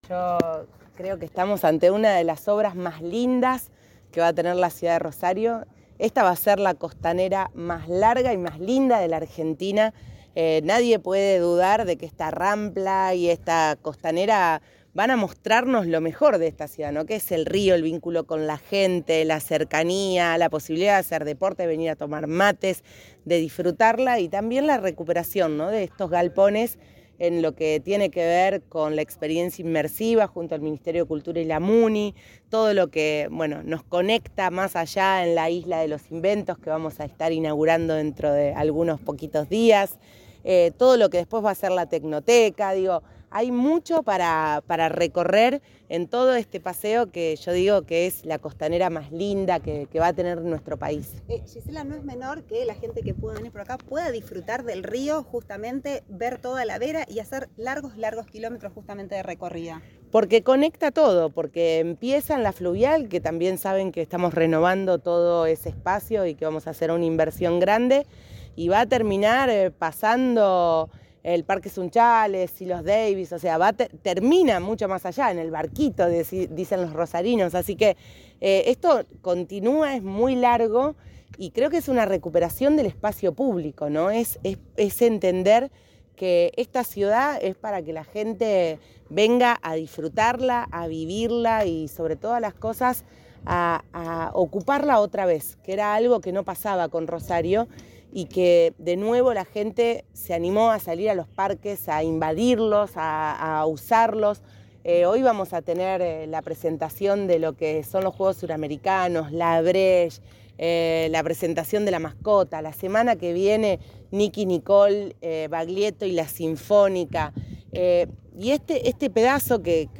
La vicegobernadora de Santa Fe recorrió las obras de la nueva costanera de Rosario y, en el marco de la visita, se refirió al diputado y candidato libertario José Luis Espert tras la denuncia que lo vincula con el narcotráfico.
Informe